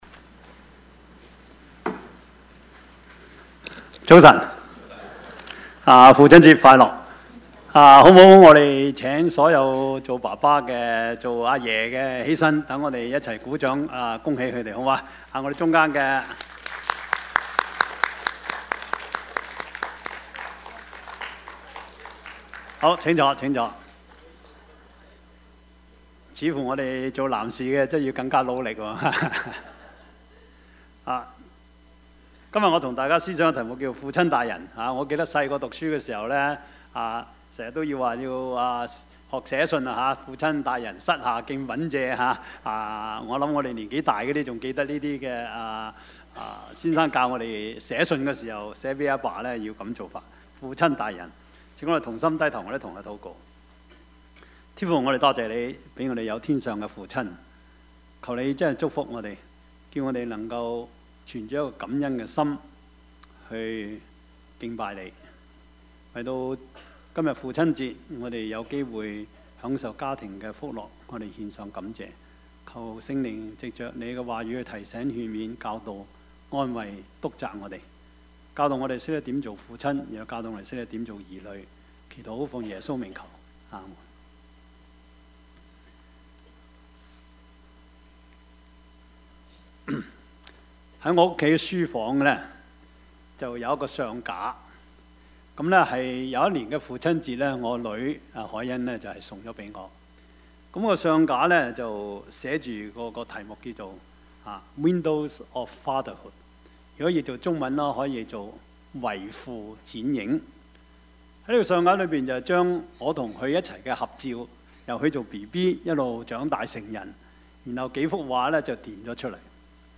Service Type: 主日崇拜
Topics: 主日證道 « 遵行神旨意的挑戰 安於暴風 »